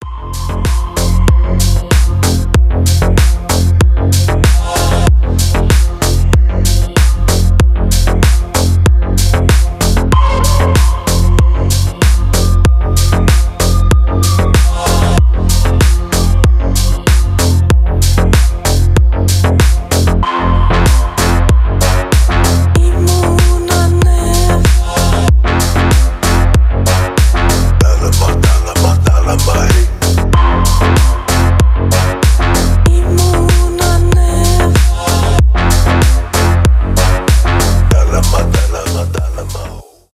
клубные
house